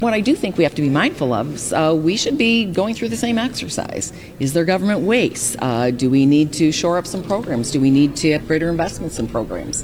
Representative Ann Bollin, who is the Republican chairwoman of the House Appropriations Committee, says the state needs to find places to cut spending wherever possible, and follow the example of President Trump.